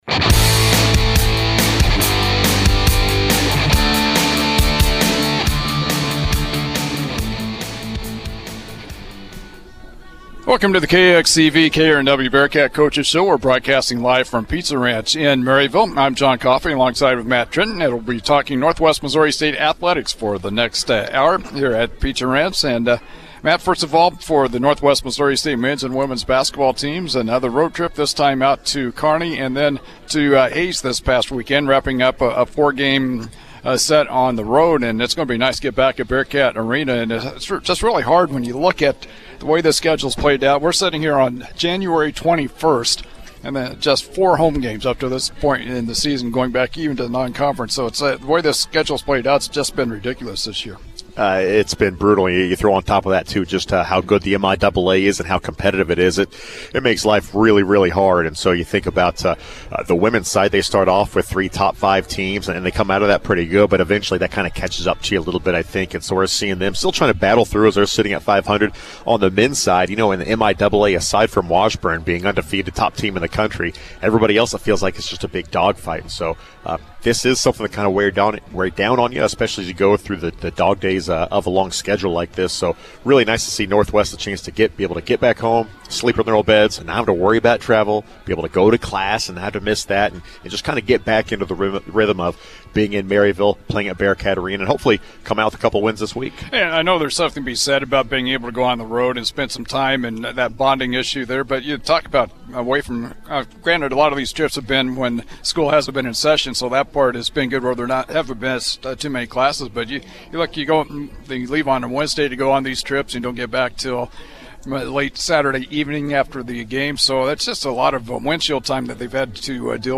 Download .mp3 Northwest Missouri State indoor track & field hosted its first competition of 2025, while Bearcat basketball finally gets the opportunity to play at home once again. We hear from coaches and student-athletes from each squad on this week's Bearcat Coaches Show.